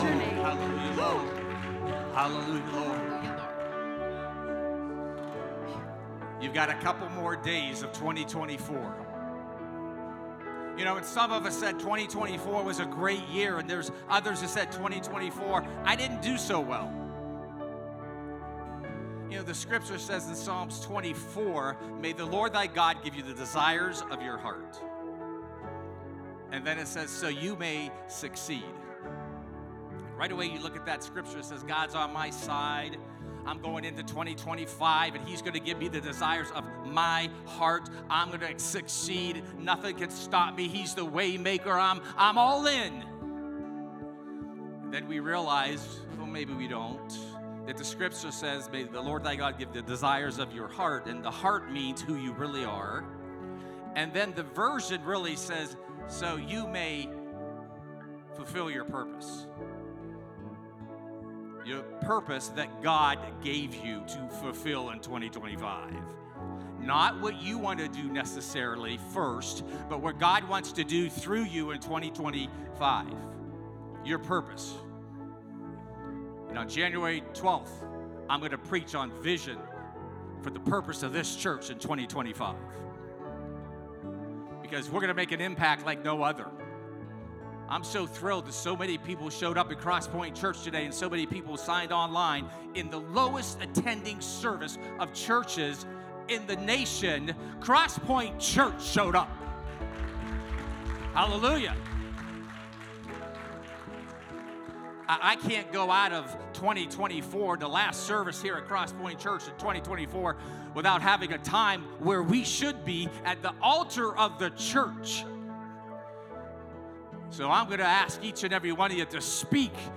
More Messages from Guest Speaker | Download Audio